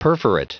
Prononciation du mot : perforate
perforate.wav